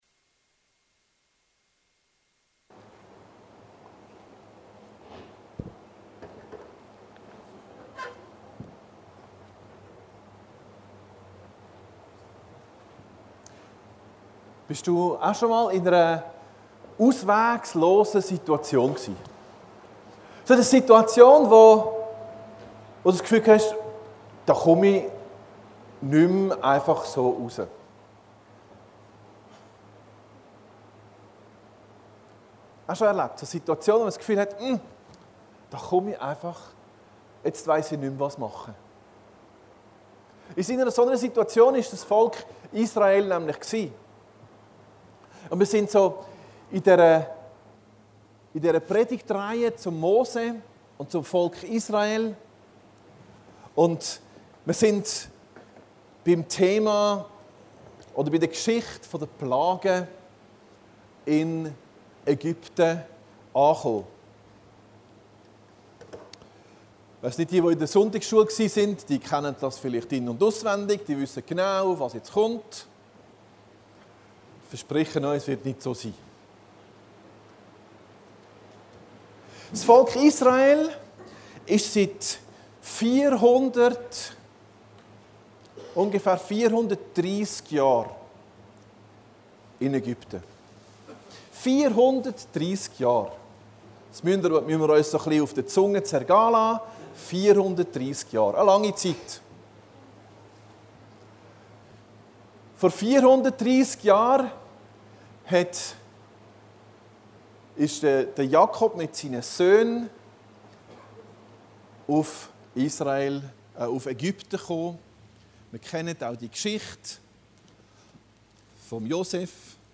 Predigten Heilsarmee Aargau Süd – 2.